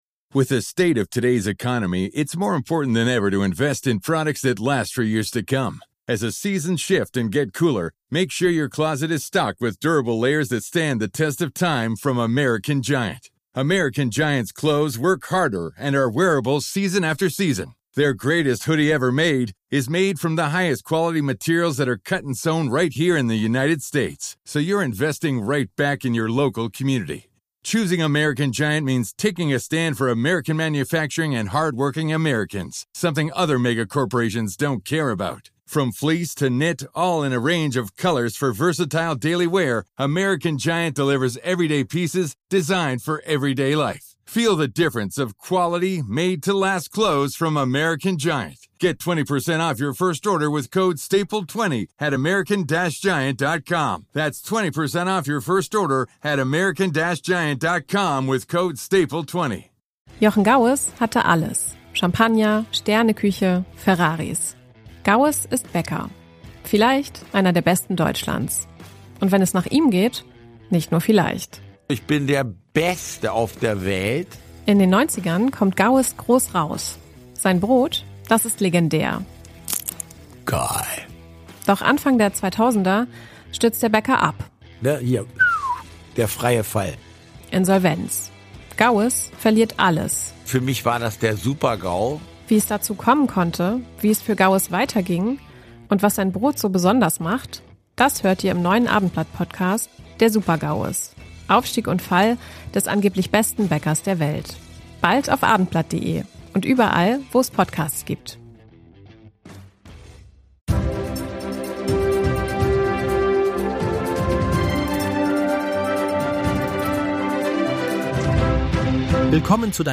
Hamburg-News - der aktuelle Nachrichten-Überblick um 17 h